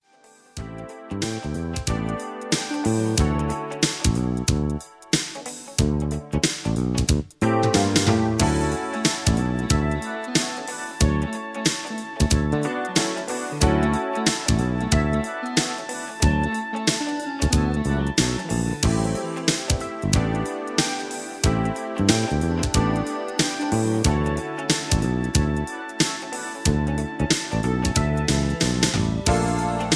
mp3 backing tracks